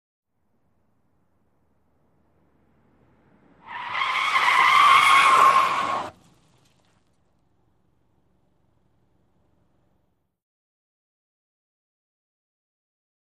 Mid Size Sedan Accelerate Quickly, Sudden Brake Into 180 Degree Skid, Throws Gravel-L To R